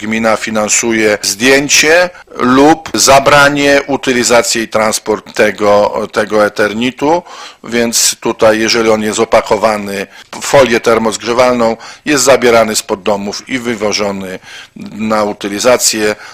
Mówi wiceburmistrz Paweł Rędziak: